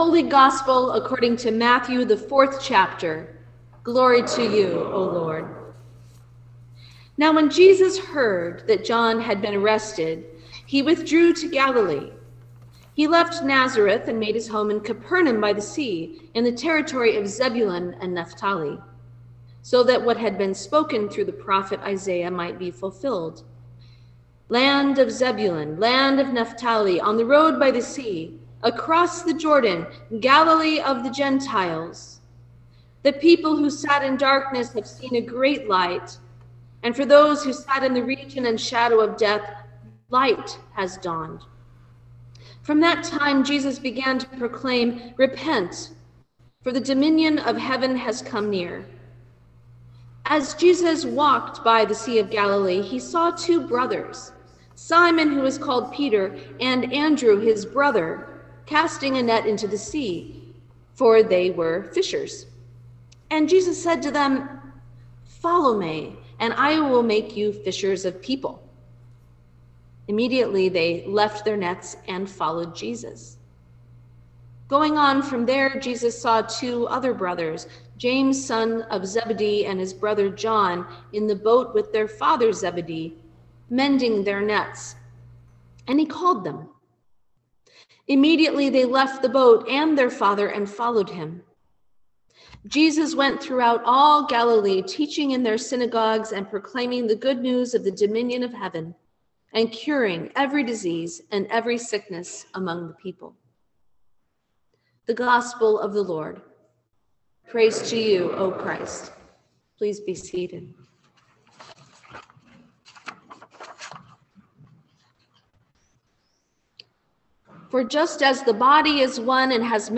Sermon for the Week of Prayer for Christian Unity 2023